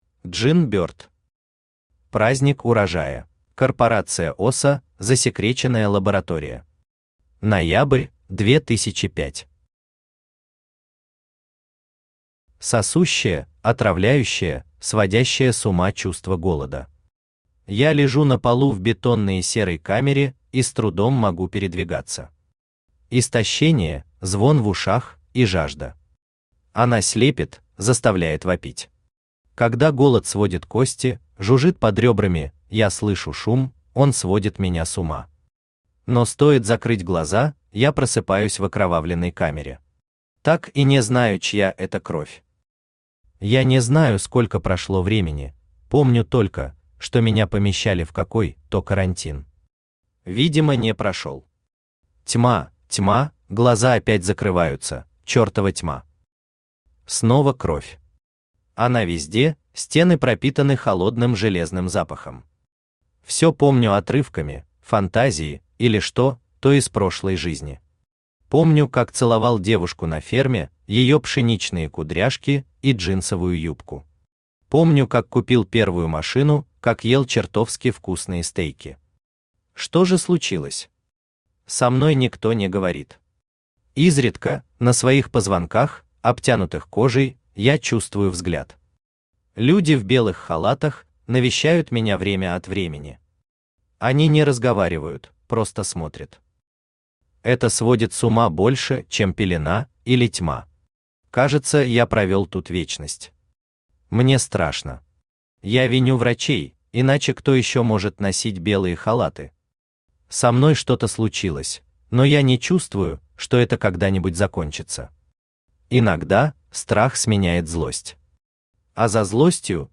Aудиокнига Праздник урожая Автор Джин Бёрд Читает аудиокнигу Авточтец ЛитРес.